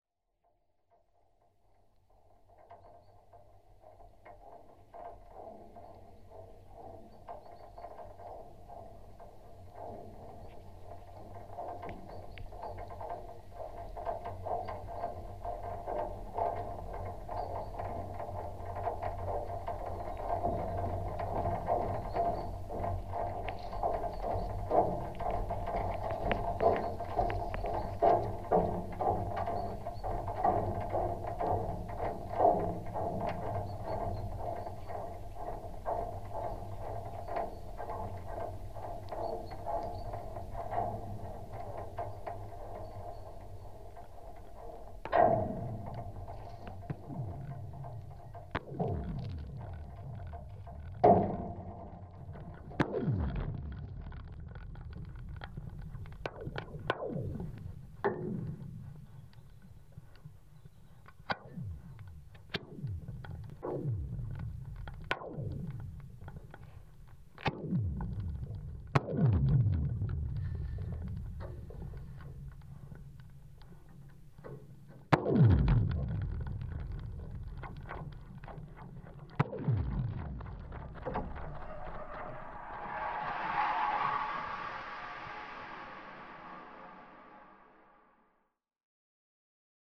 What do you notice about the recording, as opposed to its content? Pitlochry Suspension Bridge